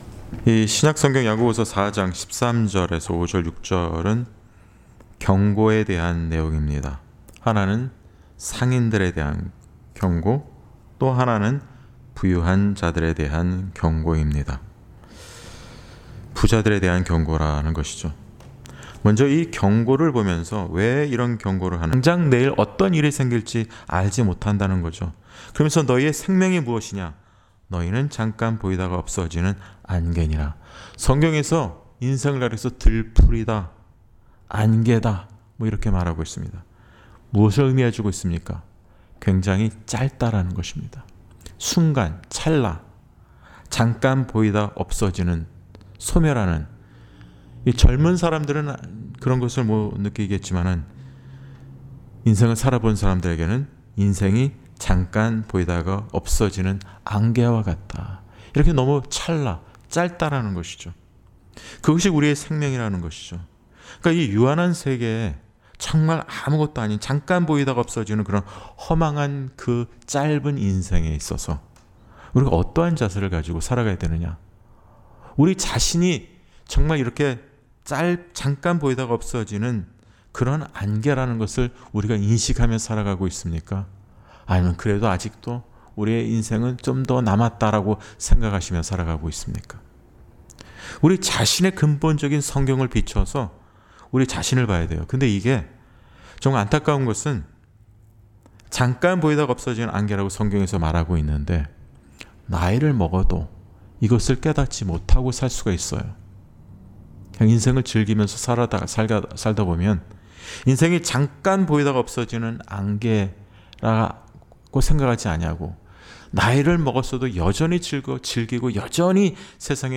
Series: 금요기도회